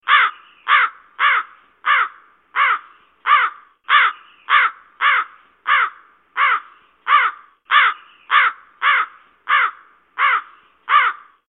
• Качество: 128, Stereo
карканье
Может кому-то нравится как каркает ворона...